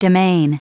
Prononciation du mot demesne en anglais (fichier audio)
Prononciation du mot : demesne